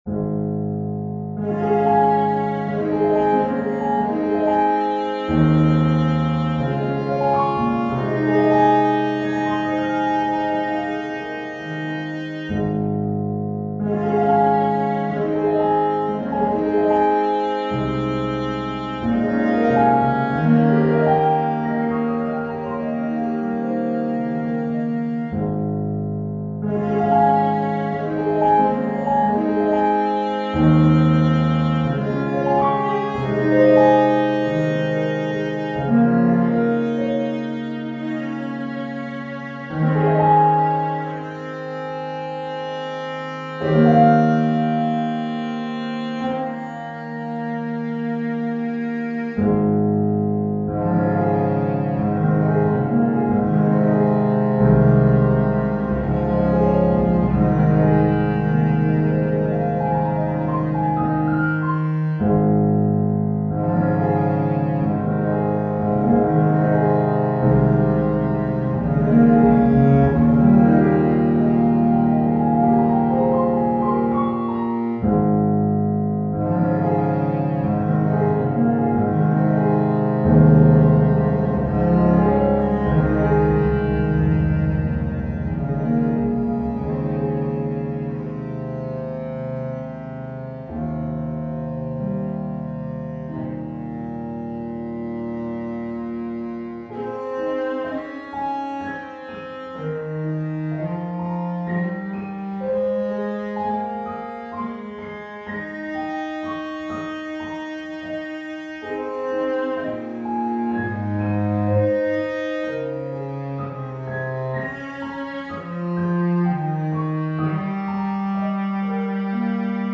classical, inspirational, contemporary
Ab major
♩=46 BPM